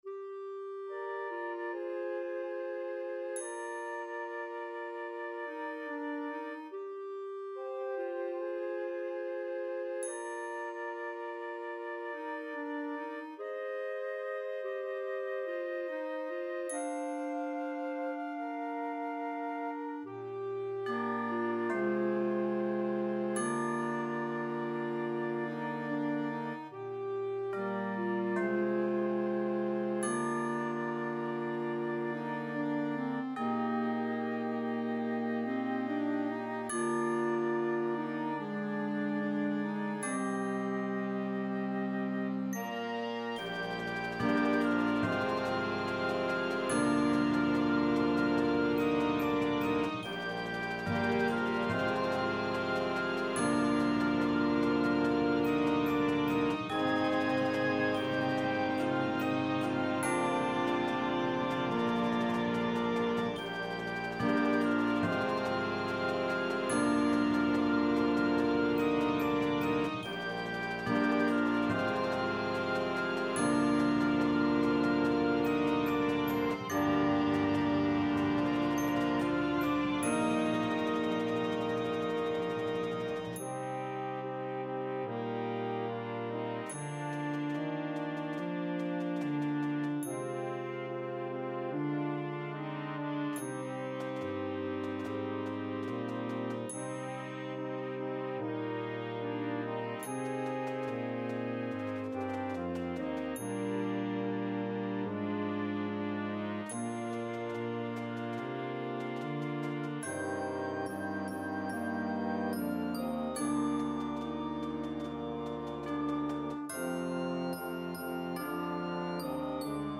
wind band